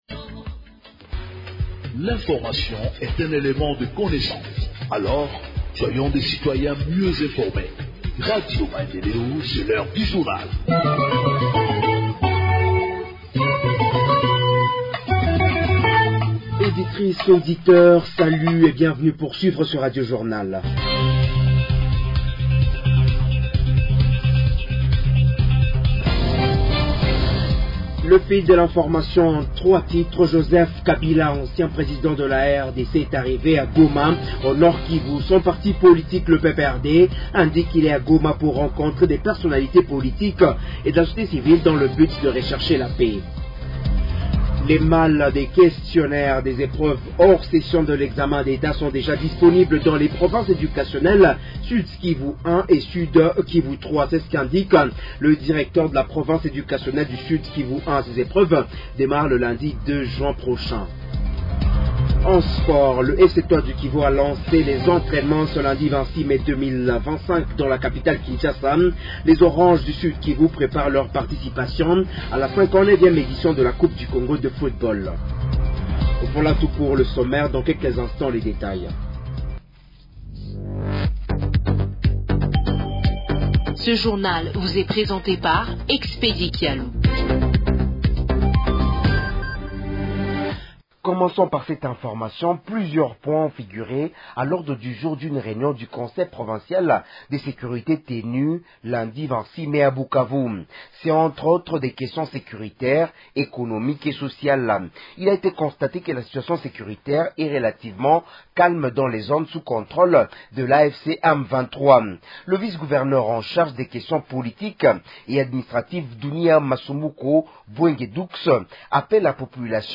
Journal en Français du 27 Mai 2025 – Radio Maendeleo